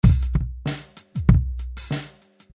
嘻哈大鼓3
嘻哈说唱原声鼓鼓点
Tag: 96 bpm Hip Hop Loops Drum Loops 431.09 KB wav Key : Unknown